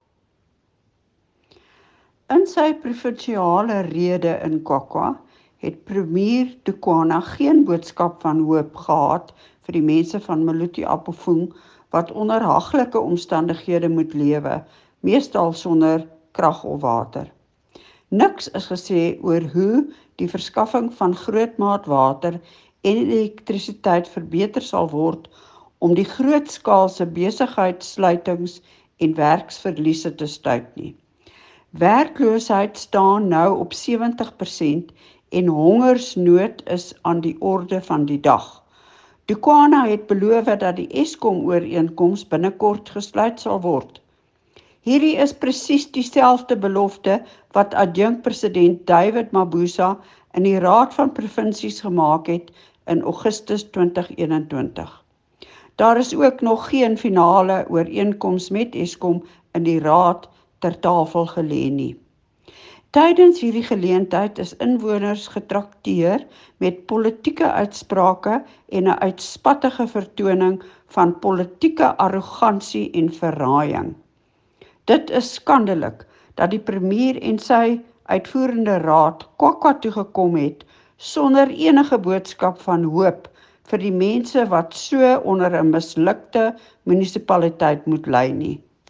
Afrikaans soundbites by Leona Kleynhans MPL and